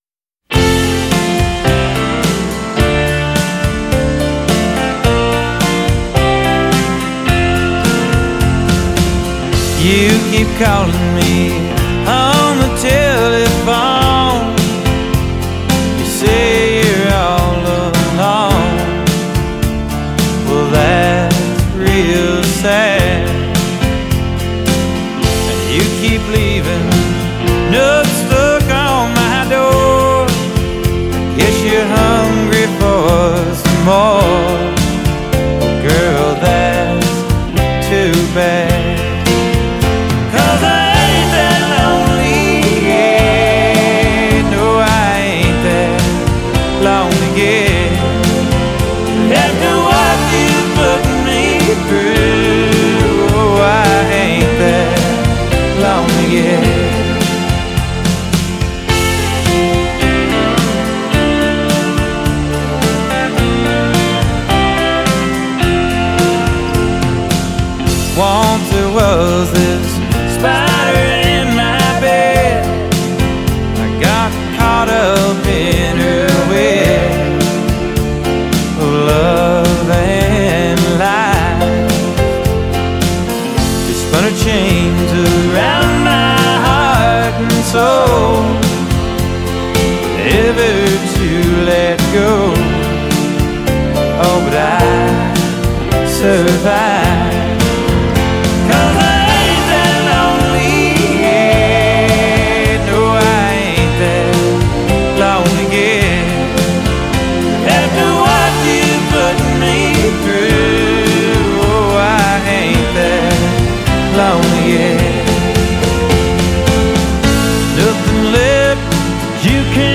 Bakersfield sound